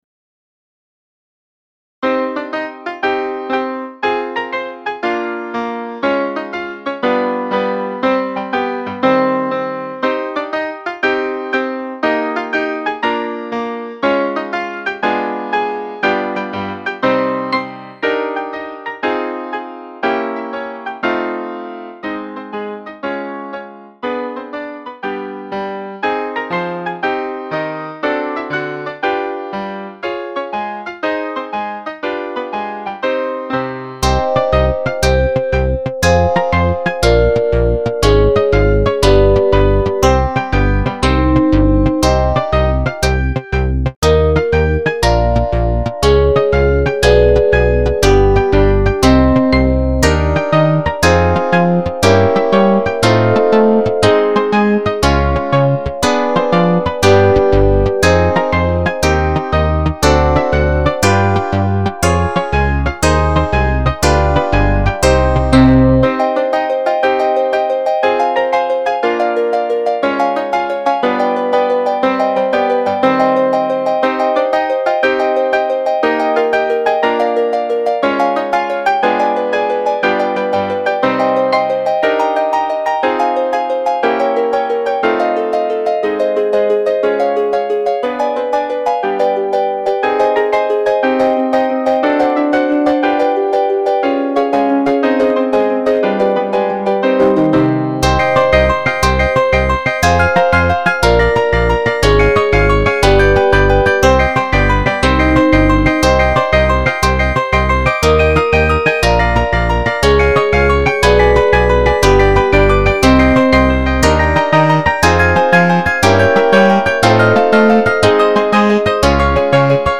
I am redoing some of my MIDI files from years ago and I am adding stereo effects.
EXPERIMENTAL MUSIC